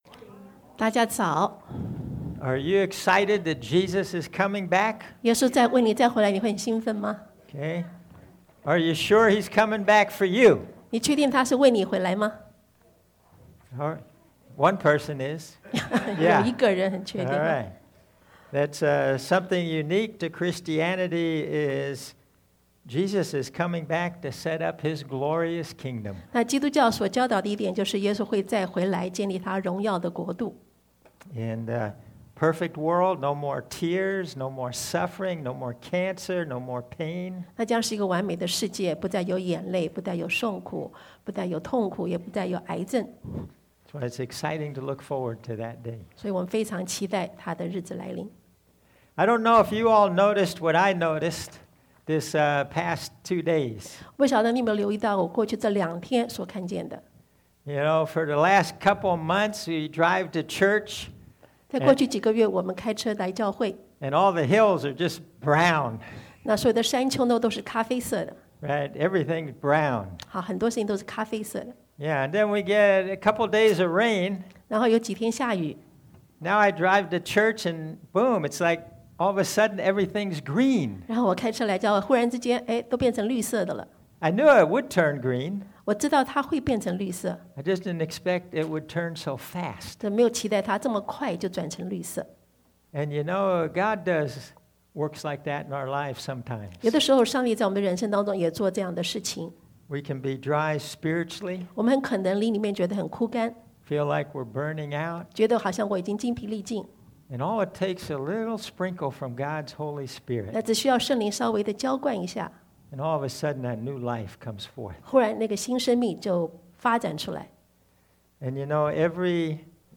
Passage: Matt. 6:9 Service Type: Sunday AM